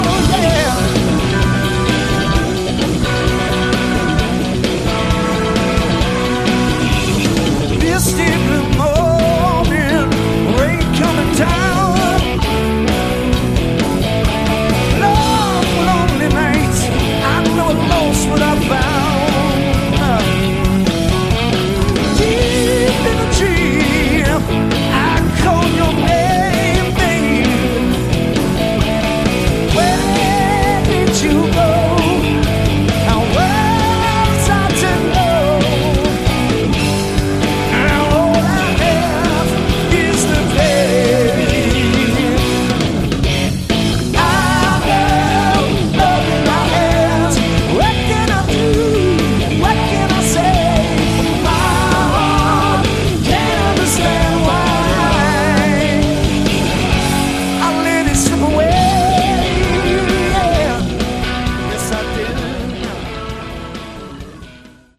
Category: Melodic Hard Rock
Vocals, Guitar
Bass
Keyboards
Drums
Live